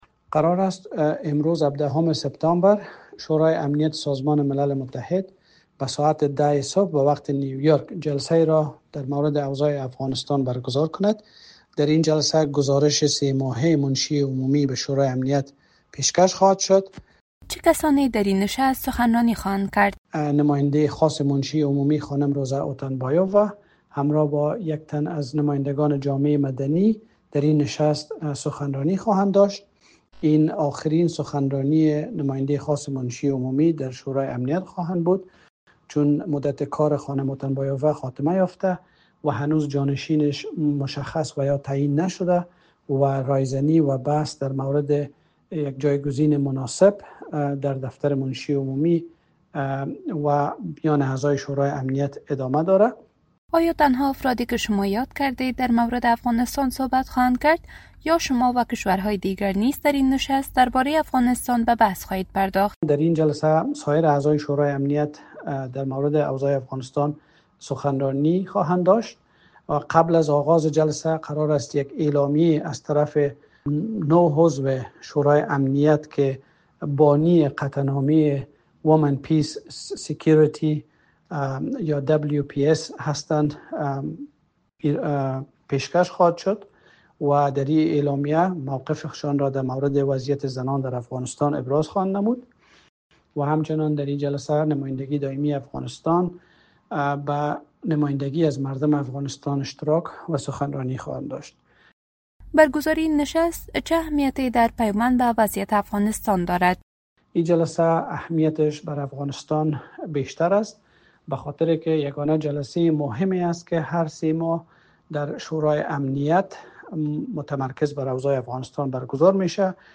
مصاحبه با نصیر احمد فایق، سرپرست نمایندگی دایمی افغانستان در سازمان ملل